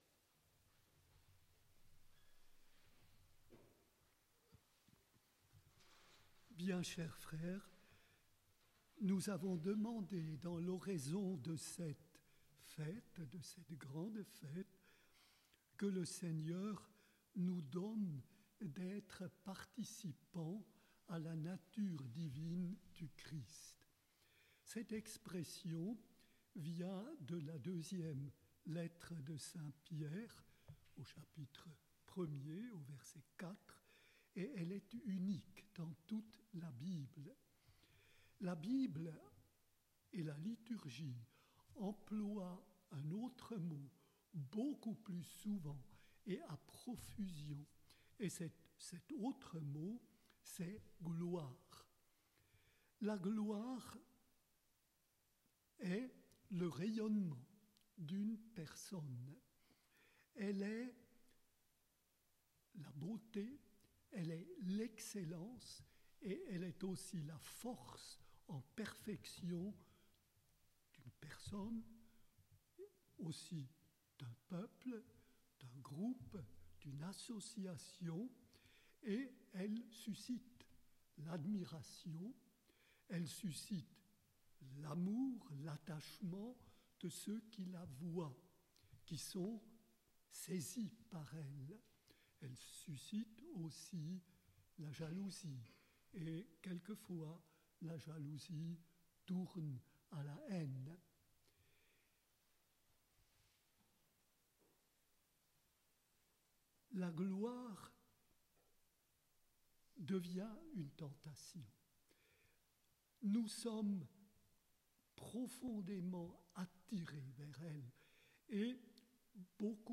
Réédition : Une homélie pour l'Annonciation
Ce matin, nous avons célébré la messe à huis clos pour la solennité de l'Annonciation du Seigneur, une grande fête de l'Église et la fête patronale de la Province de Suisse de l'Ordre des prêcheurs.
L'écoute de cette homélie, enregistrée en direct pendant la messe, est une manière de vous joindre à la communauté alors que nous contemplons la Sainte Ecriture avec un guide expérimenté.